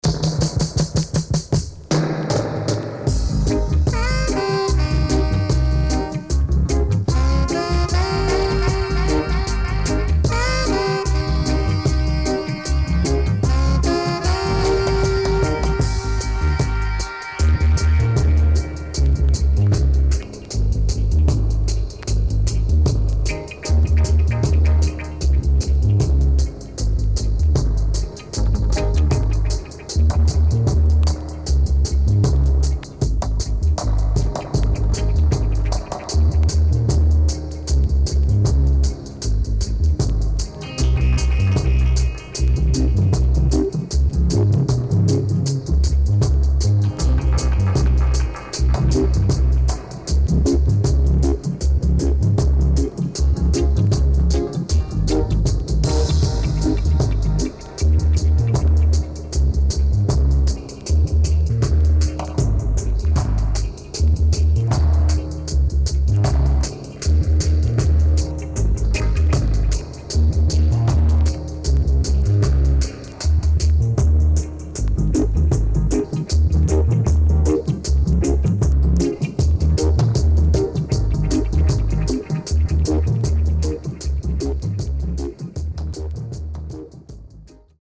SIDE B:所々チリノイズがあり、少しプチノイズ入ります。